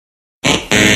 Descarga de Sonidos mp3 Gratis: incorrecto chicharra.
correct-buzzer.mp3